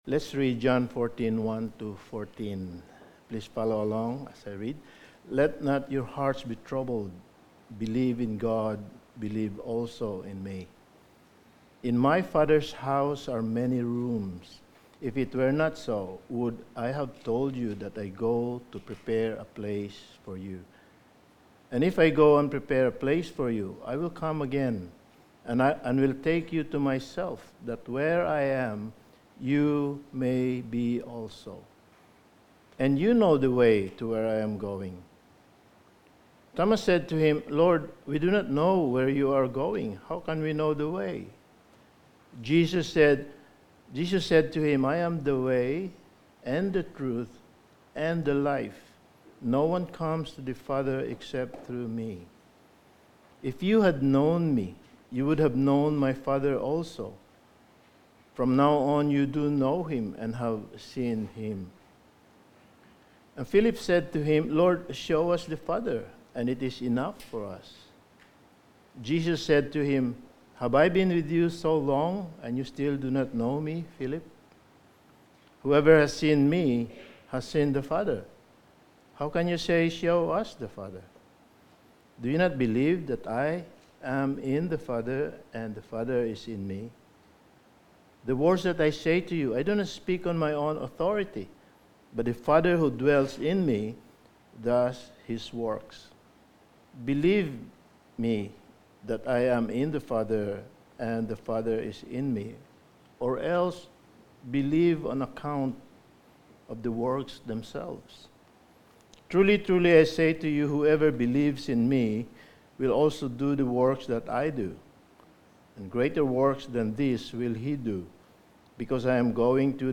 Passage: John 14:1-14 Service Type: Sunday Morning